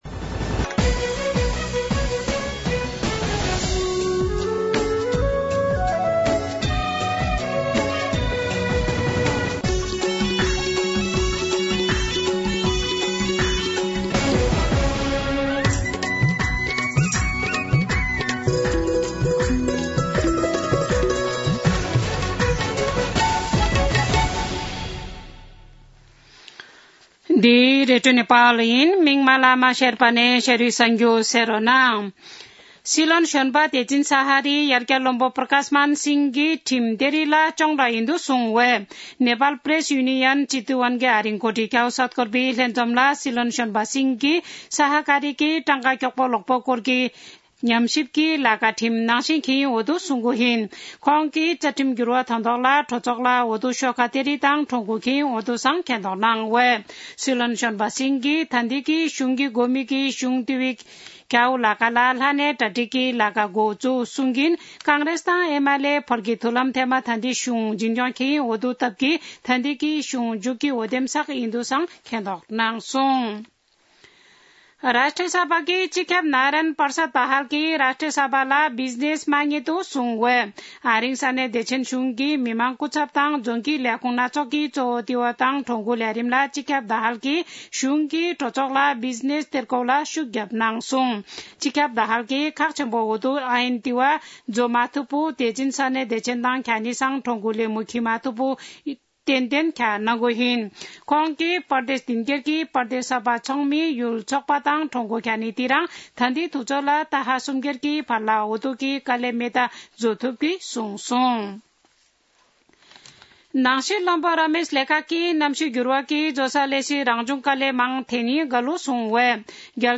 शेर्पा भाषाको समाचार : १२ पुष , २०८१
Sherpa-News-5.mp3